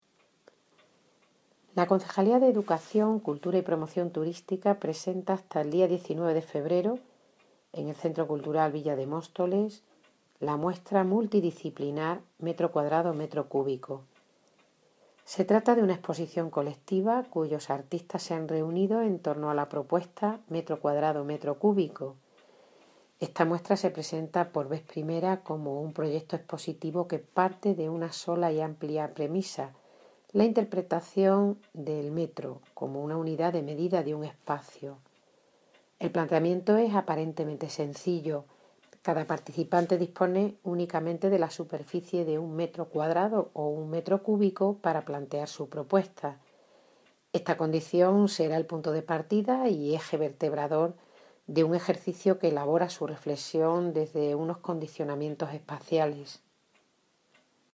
Audio - Mirina Cortés (Concejal de educación Cultura y Promoción turistica) Sobre Exposición m2 m3 en el CCVM